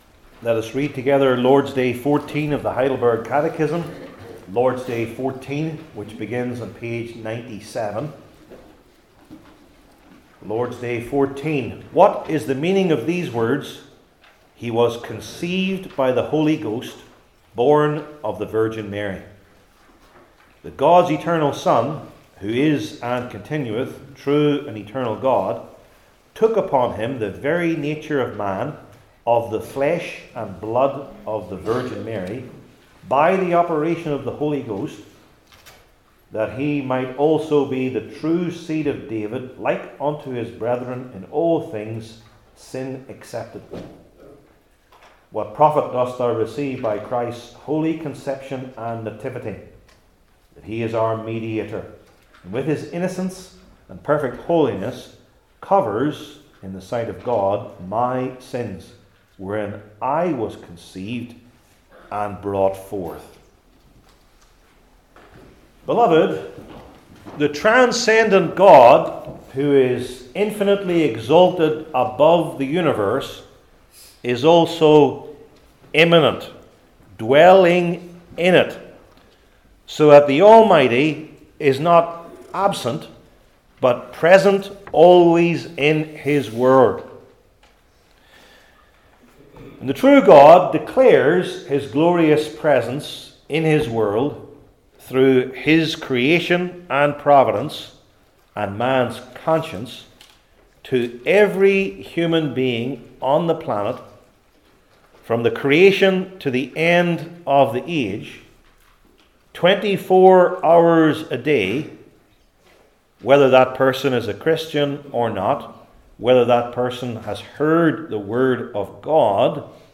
Heidelberg Catechism Sermons I. In the Old Testament Age II.